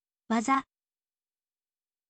waza